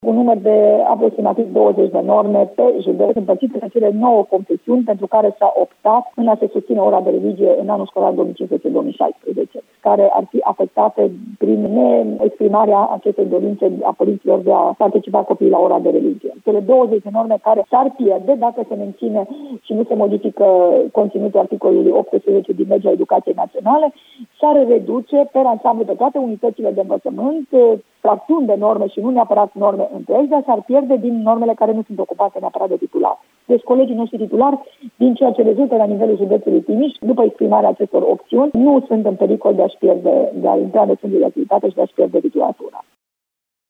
Potrivit şefului inspectoratului şcolar, Aura Danielescu, 10% din normele de religie ar putea fi afectate începând cu următorul an şcolar:
Aura-Danielescu-ora-de-religie.mp3